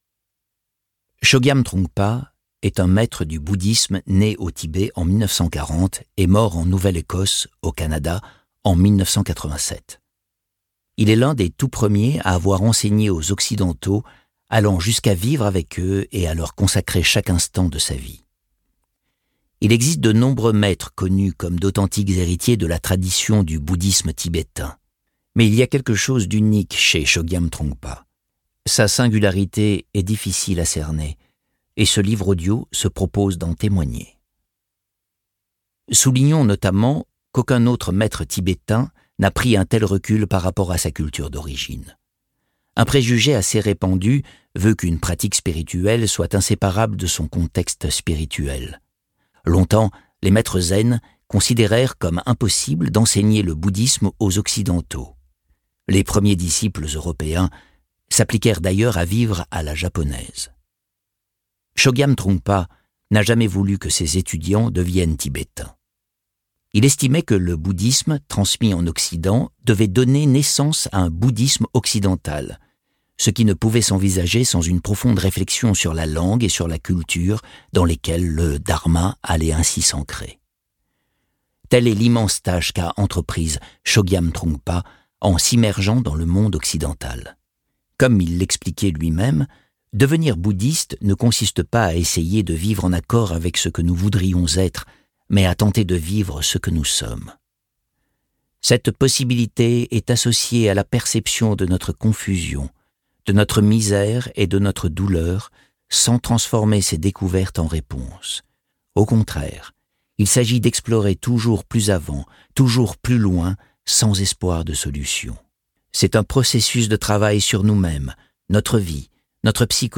je découvre un extrait - Trungpa : L'homme qui a introduit le bouddhisme en Occident - Par l'auteur du best-seller " Foutez-vous la paix ! " de Fabrice Midal